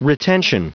Prononciation du mot retention en anglais (fichier audio)
Prononciation du mot : retention